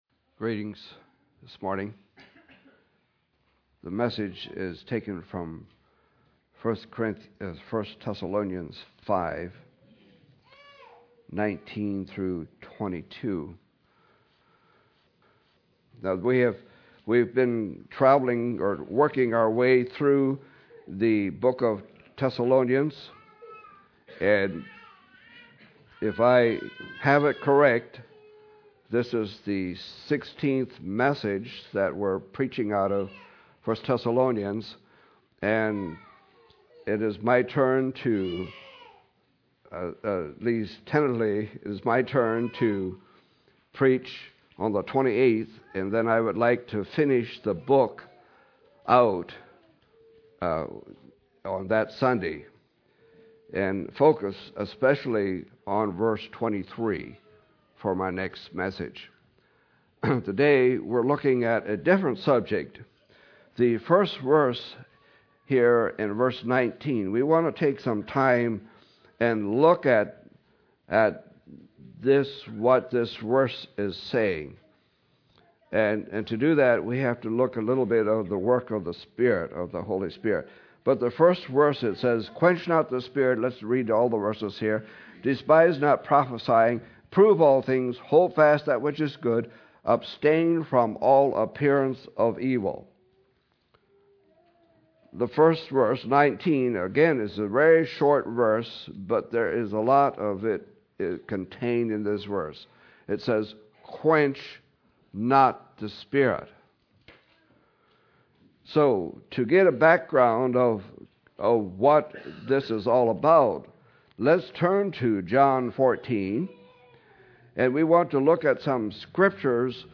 December 7, 2014 – Crosspointe Mennonite Church
Message